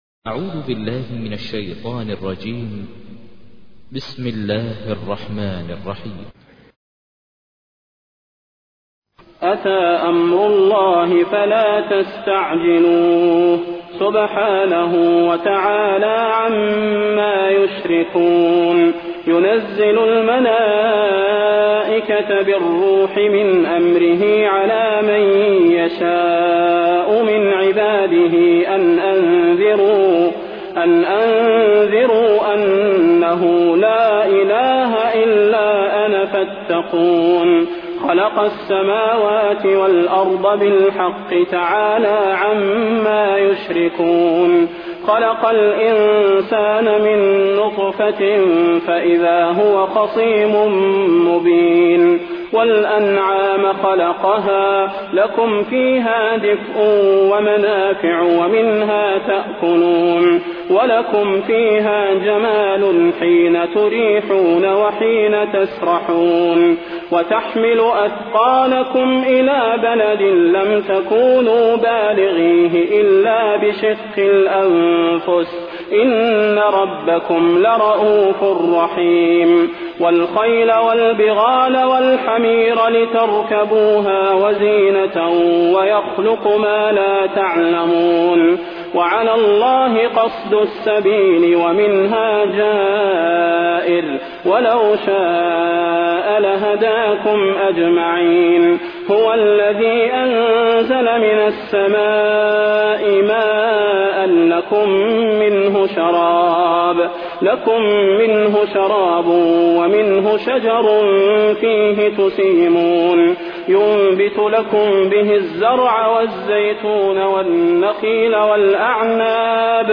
تحميل : 16. سورة النحل / القارئ ماهر المعيقلي / القرآن الكريم / موقع يا حسين